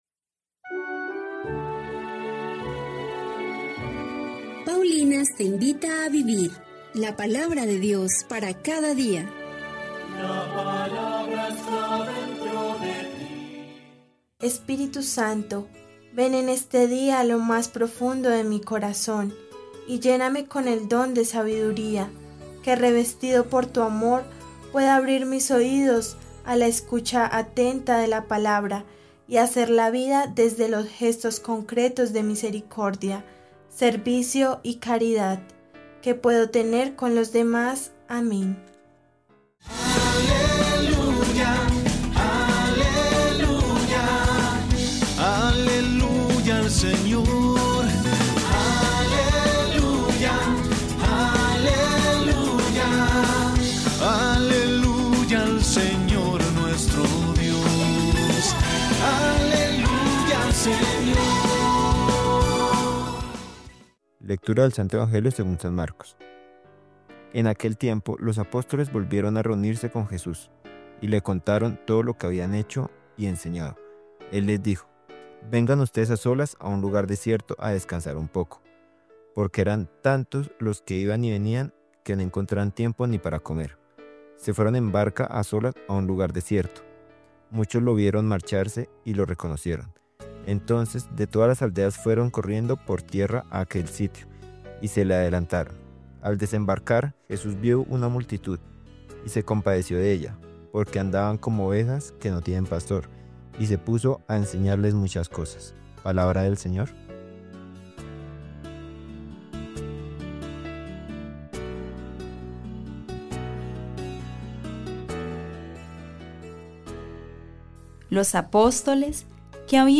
Lectura del Primer libro de los Reyes 11, 4-13